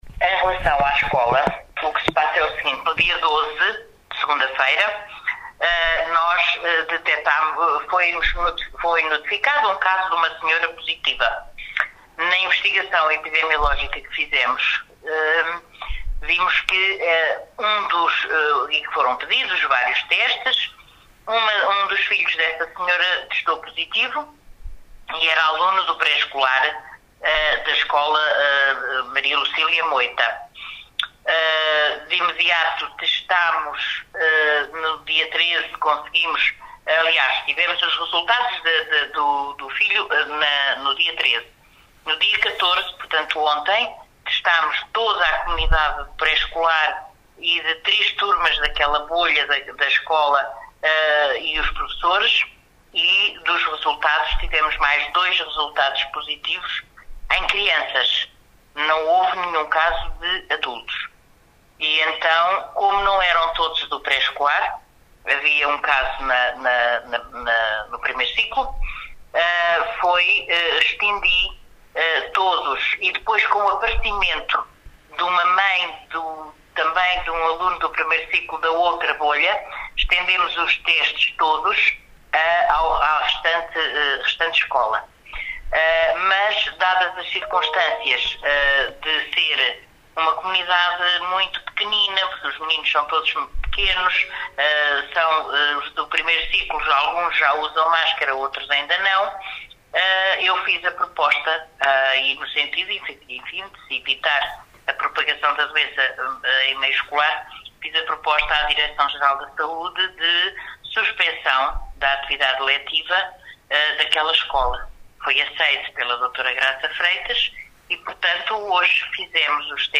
ÁUDIO: MARIA ANJOS ESPERANÇA, DELEGADA SAÚDE PÚBLICA: